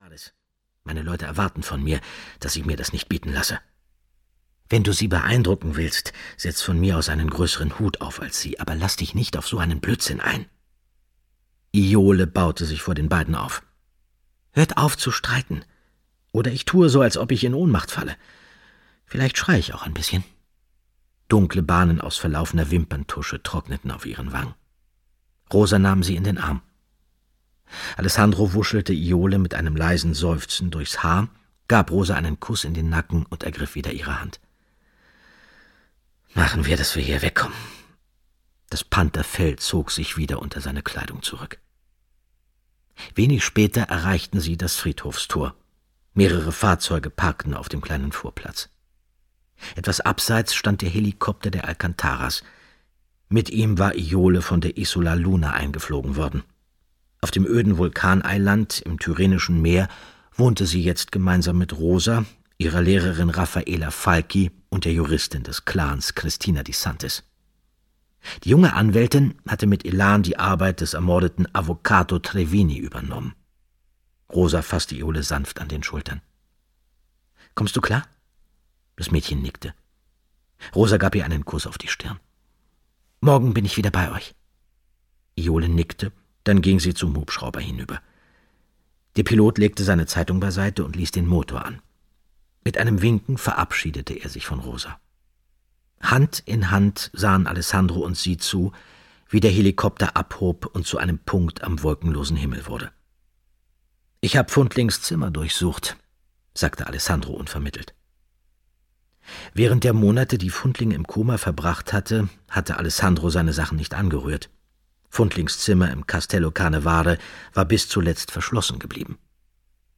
Hörbuch Arkadien-Reihe 3: Arkadien fällt, Kai Meyer.